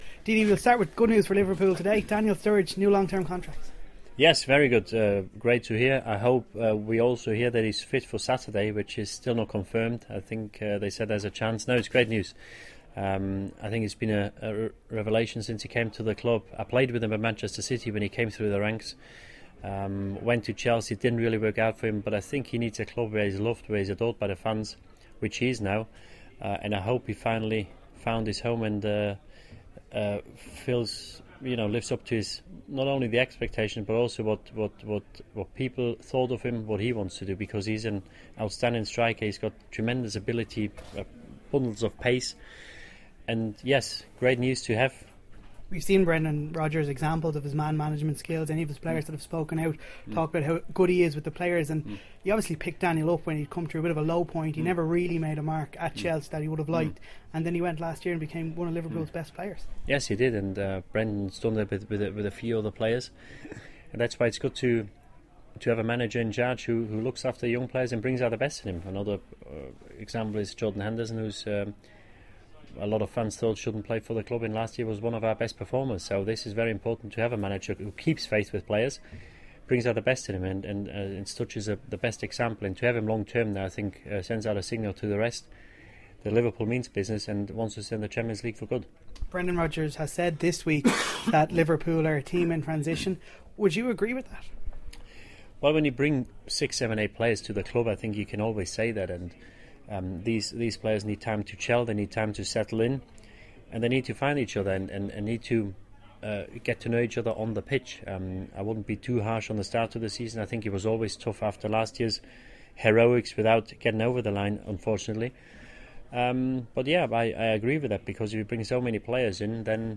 Speaking at the opening of Liverpoo's new Dublin store in the Ilac Centre, the club legend says Daniel Strurridge's new contract is a big boost. He believes the club are better off long term without the unreliable Luis Suarez.